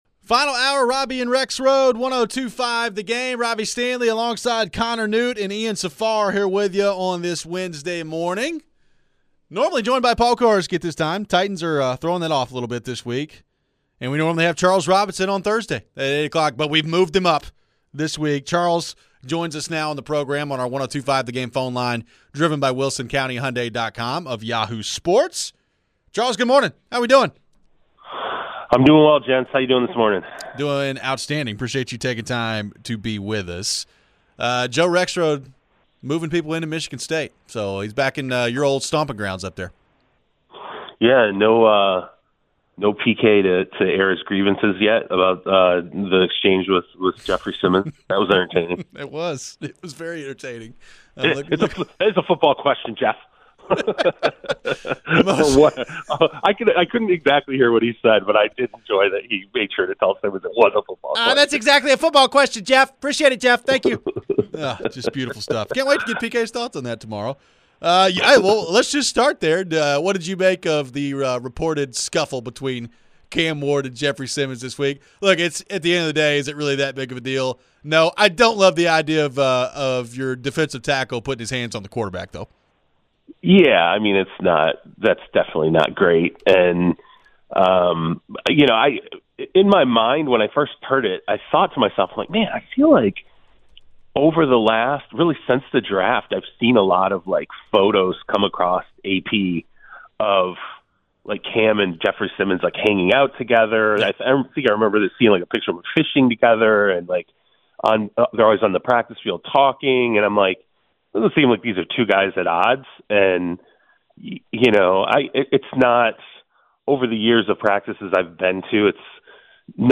Interview
We close out the show with a Titans uniform update for this week and your phones.